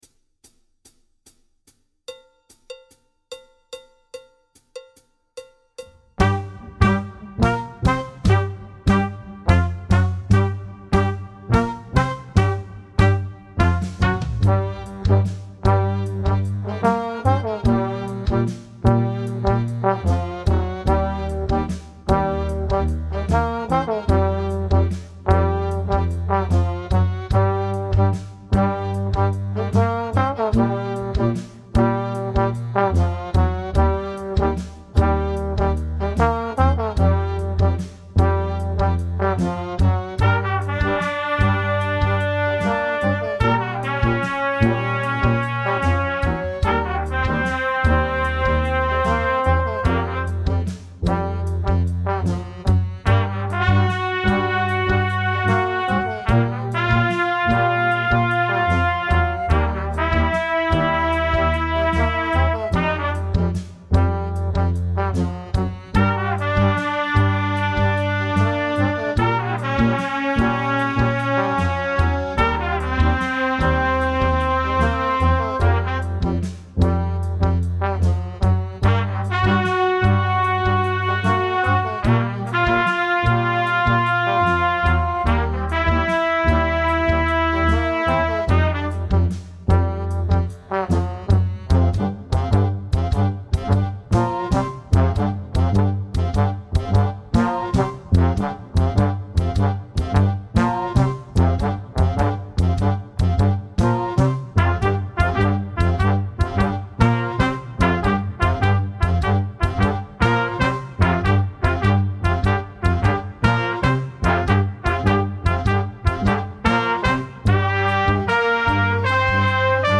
Tempo 150 & 75